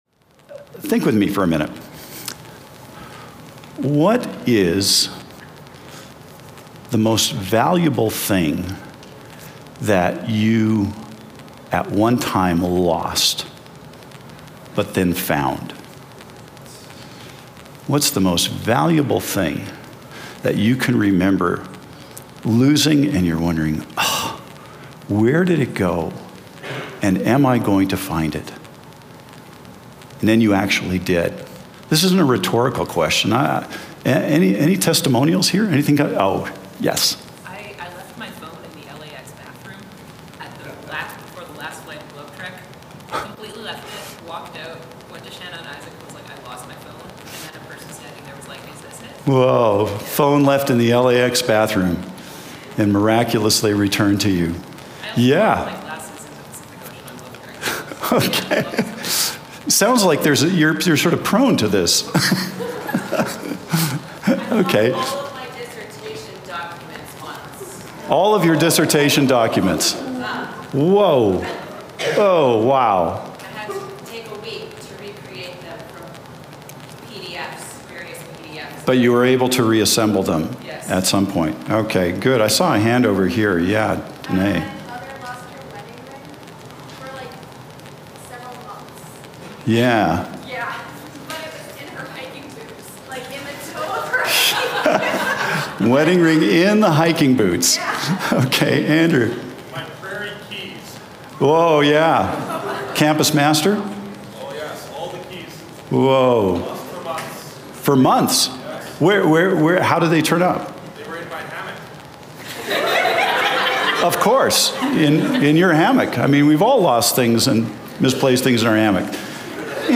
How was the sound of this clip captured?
We welcome you to join us every week for a new Community Chapel service here at Prairie College.